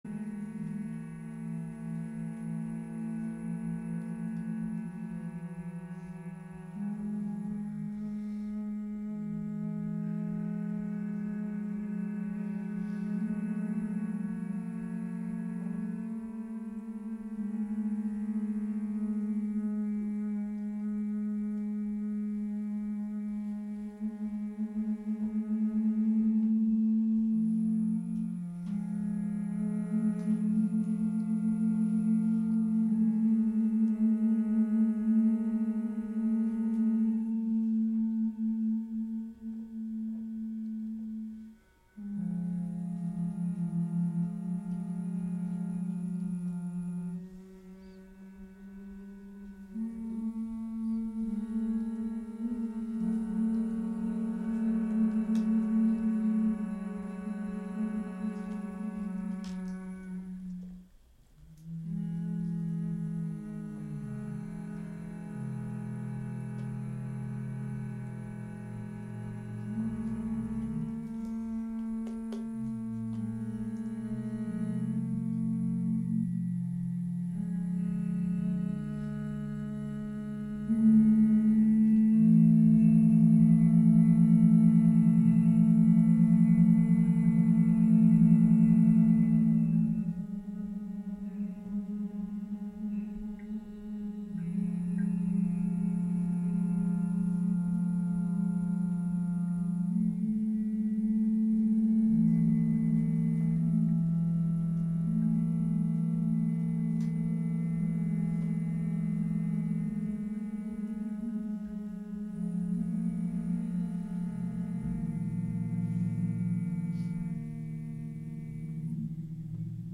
Live from Experimental Intermedia